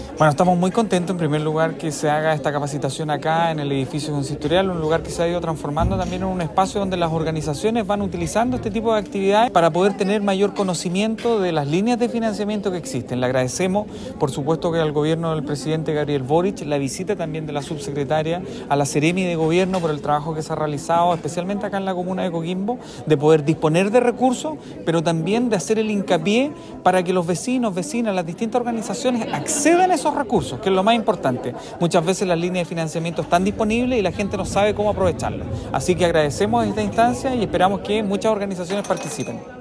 ALI-MANOUCHEHRI-ALCALDE-DE-COQUIMBO.mp3